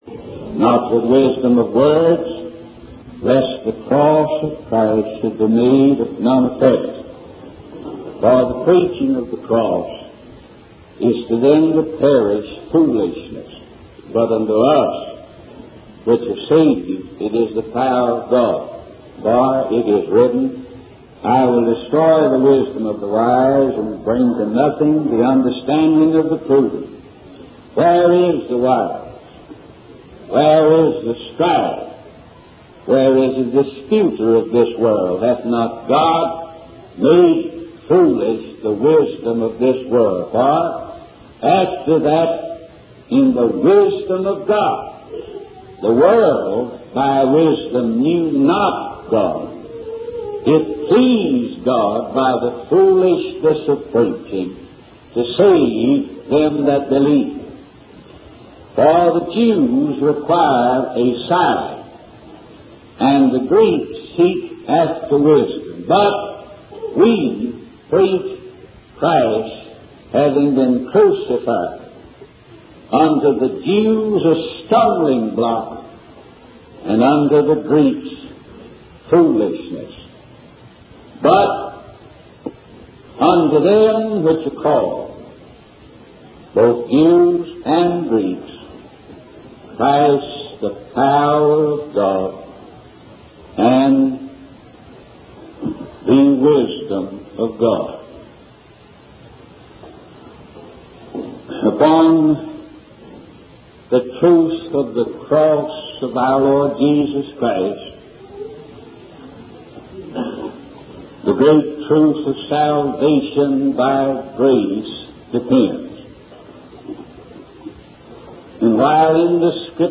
In this sermon, the preacher emphasizes the importance of having true assurance of salvation.